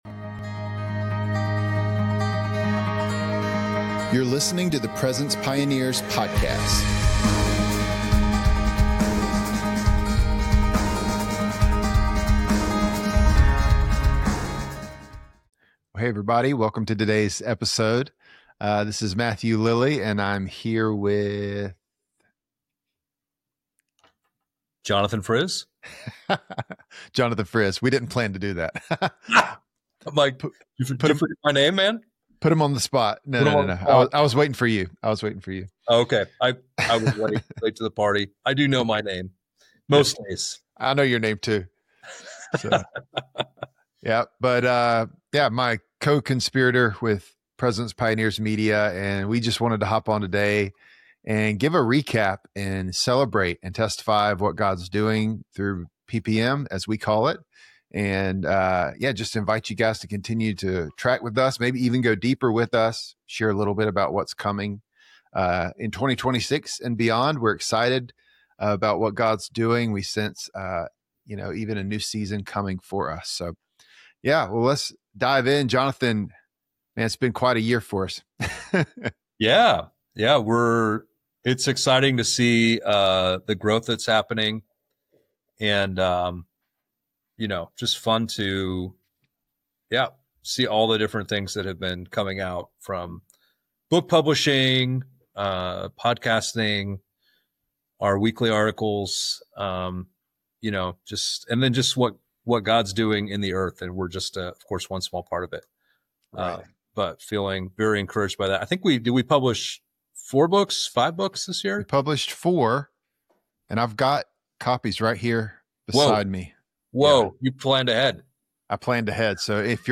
Helping worshipers, intercessors, and leaders experience and host the presence of God - because God's presence changes everything. Featuring interviews and Bible teachings from leaders in the worship & prayer movement on topics such as prophetic worship, intercessory prayer, global missions, unity in the Church, revival, and the tabernacle of David.